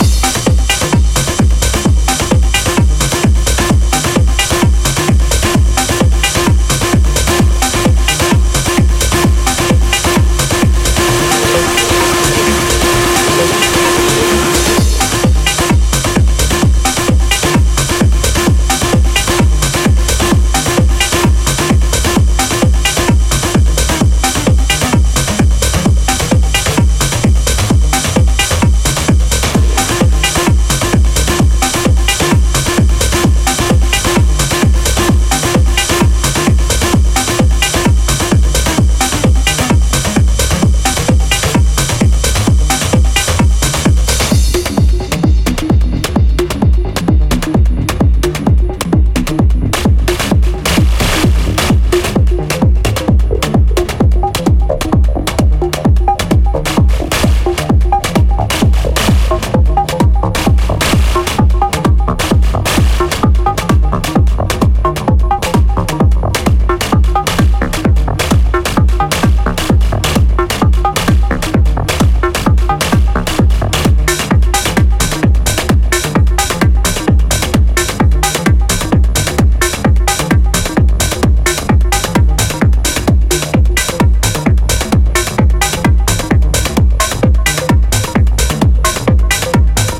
ジャンル(スタイル) TECHNO